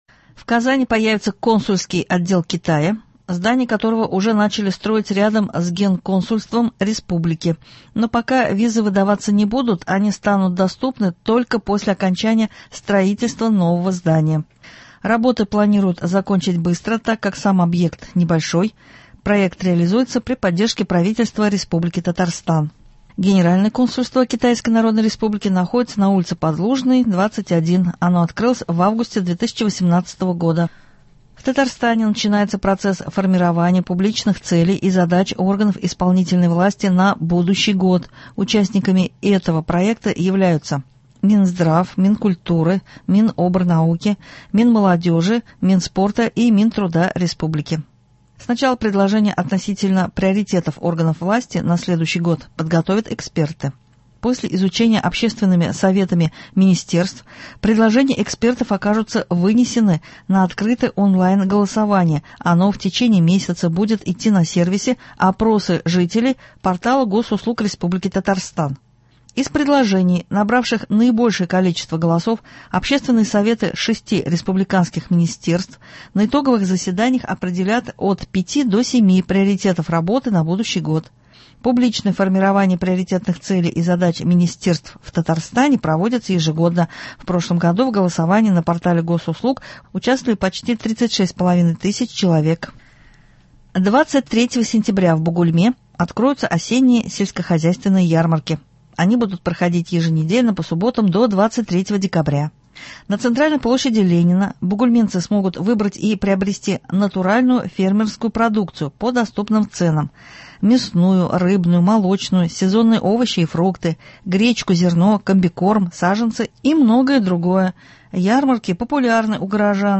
Новости (18.09.23)